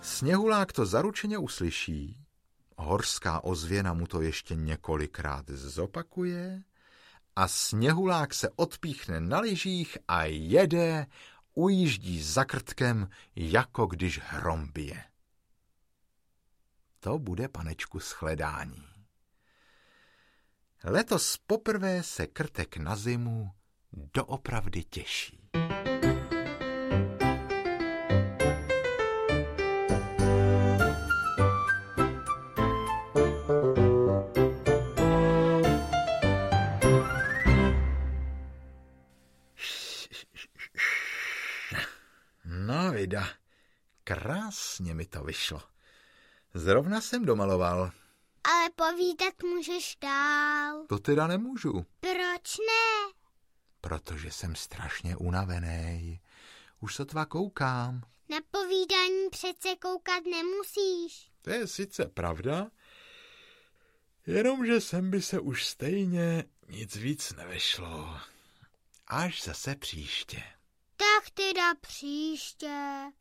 Ukázka z knihy
Další díl z cyklu dramatizovaných pohádek slavného Krtečka výtvarníka a režiséra Zdeňka Milera.
S použitím původní hudby ze stejnojmenných kreslených filmů navozuje vyprávění věrně atmosféru animovaných příběhů, které Krtečka proslavily!Obsah:Krtek a zelená hvězdaJak Krtek uzdravil myškuKrtek a sněhulákCelá série všech pěti alb Krtečkových dobrodružství od Supraphonu také existuje jako komplet.